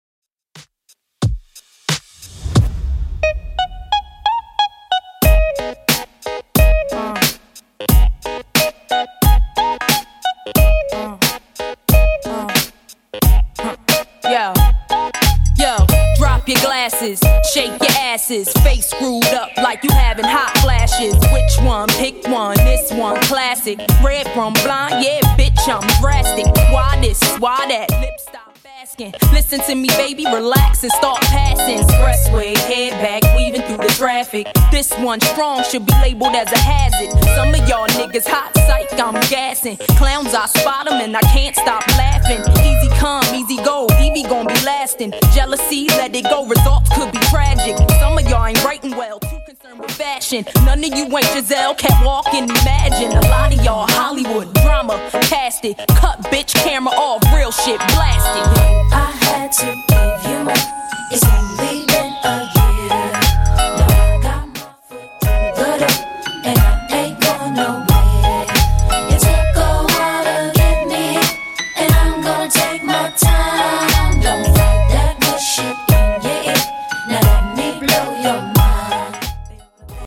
Genre: 90's
BPM: 73